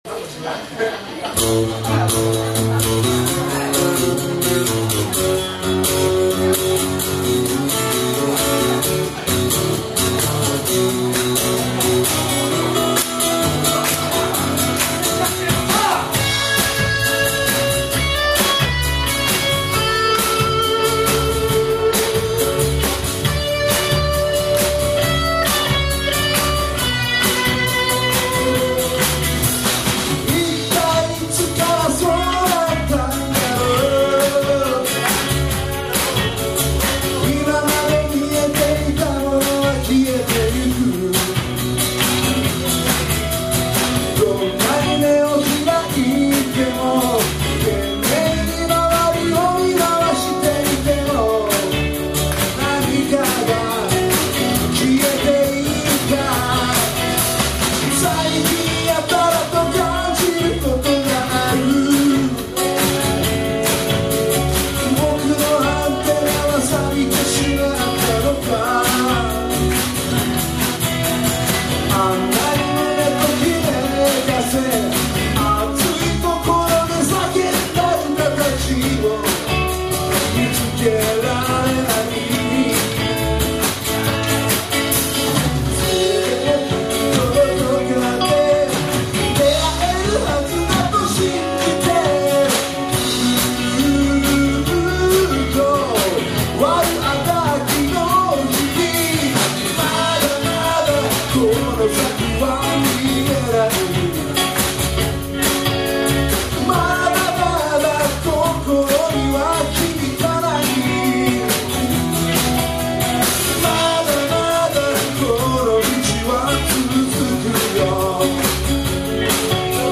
(J's bar summer live party h23�8��)